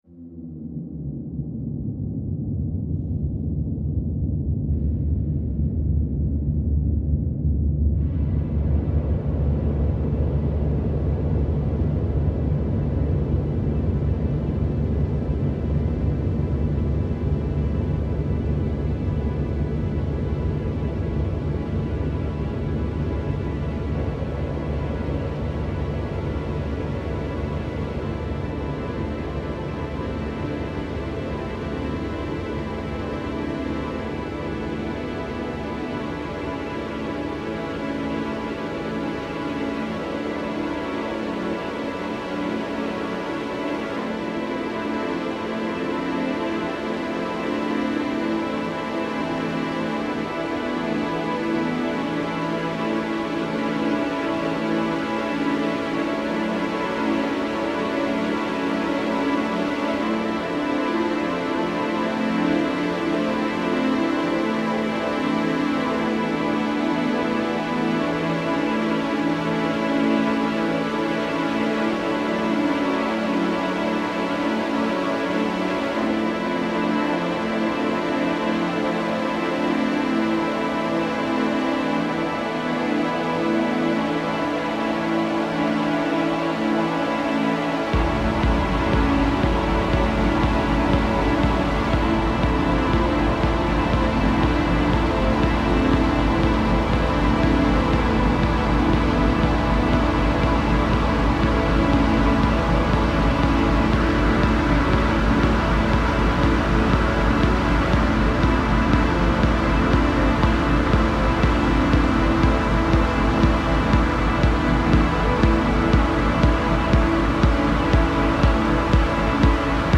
downtempo
fourteen-minute ambient house monster